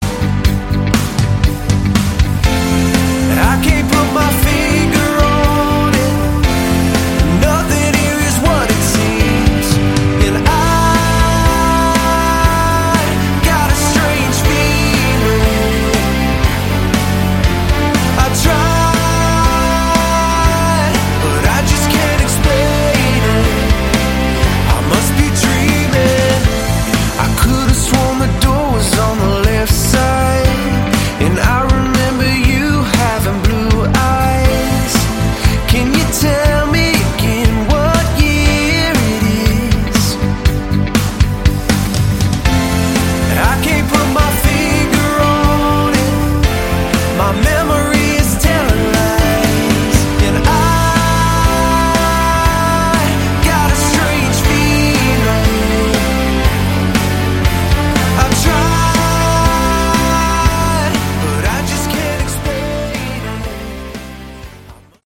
Category: Melodic Rock
Guitars, Keyboards